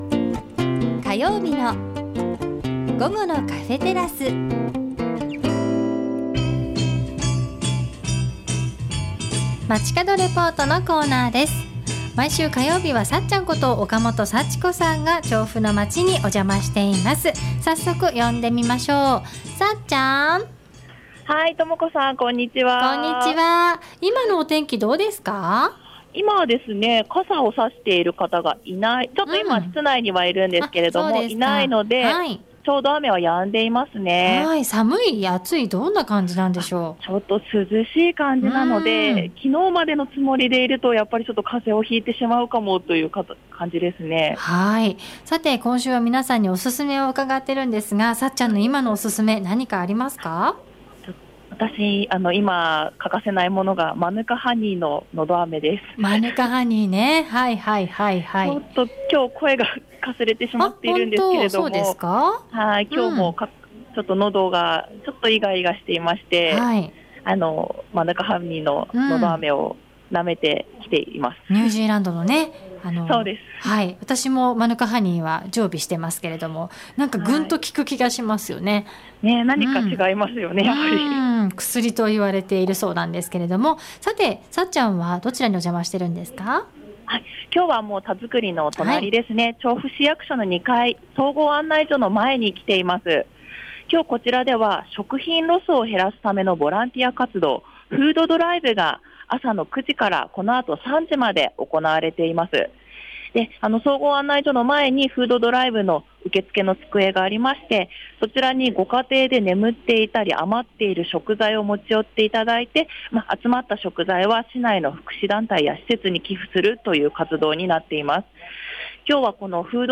今週は調布市役所の２階、総合案内所前から中継をしました。 今日こちらでは朝の９時～午後３時まで「食品ロス」を減らすためのボランティア活動「フードドライブ」が行われています。